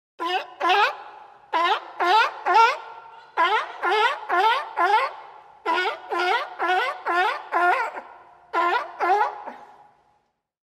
(Écoutez ici l'otarie en vous)
Sea-Lion-Sound-Effect-HD-No-Copyright-FX.mp3